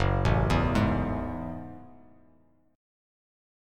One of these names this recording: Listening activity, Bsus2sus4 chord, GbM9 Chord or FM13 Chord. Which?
GbM9 Chord